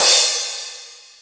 Crashes & Cymbals
JuicyCrash.wav